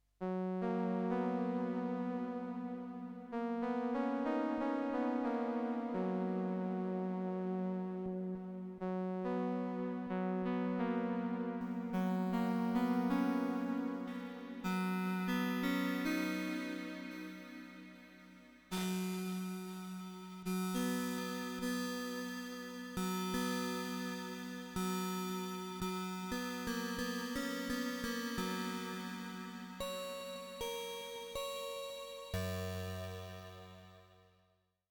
This synth is a simple 2OP FM synthesizer, based on the same chip used in the old AdLib soundcards. It has a few sliders that allow you to manipulate the FM synth in a few basic ways.
Here’s a few sound examples where I manipulated the sliders a bit: